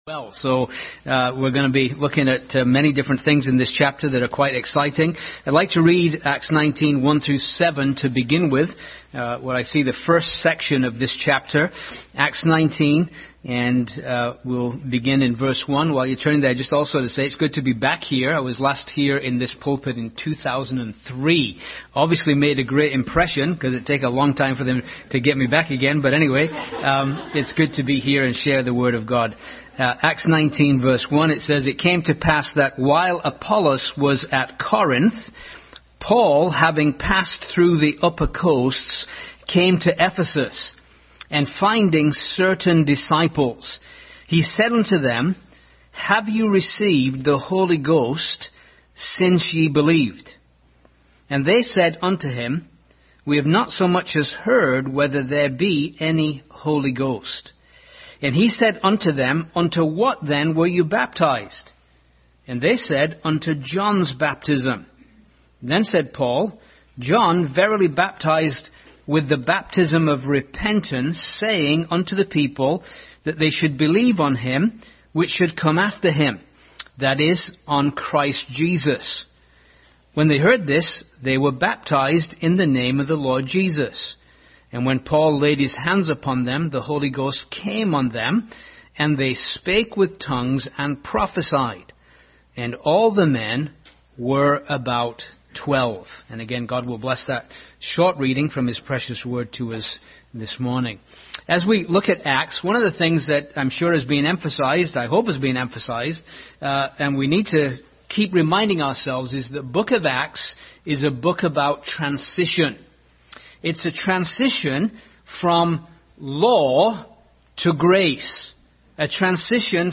Acts 19:1-7 Passage: Acts 19:1-7 Service Type: Sunday School Bible Text